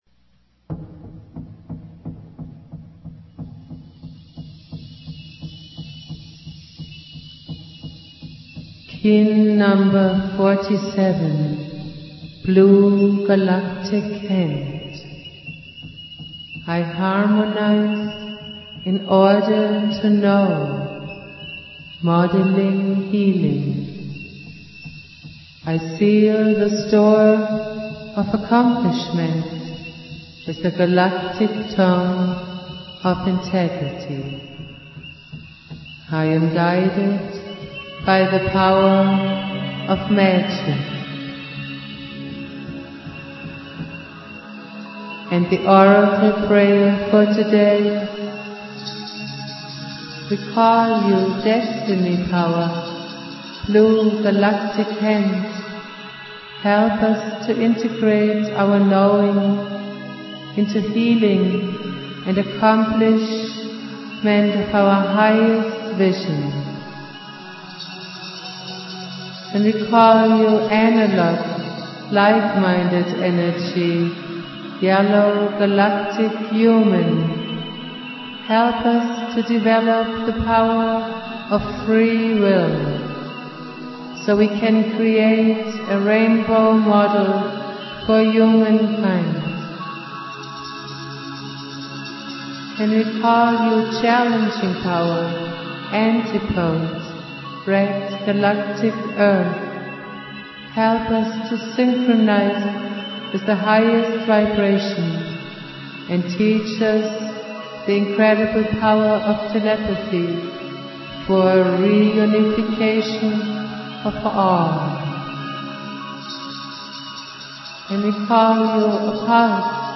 Valum Votan playing flute.
produced at High Flowing Recording Studio
Jose's spirit and teachings go on Jose Argüelles playing flute.